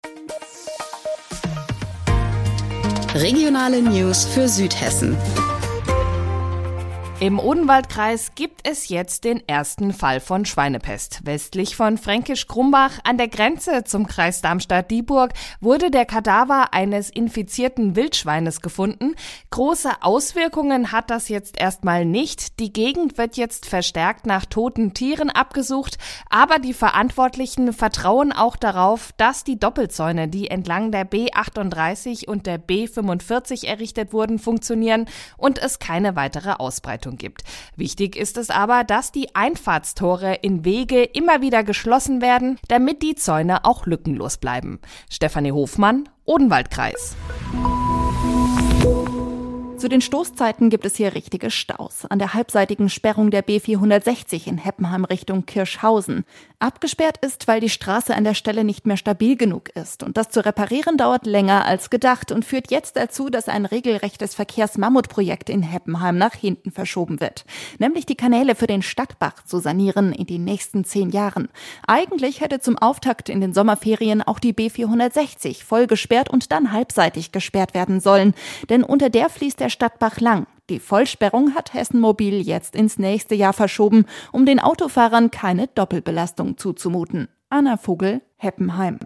Mittags eine aktuelle Reportage des Studios Darmstadt für die Region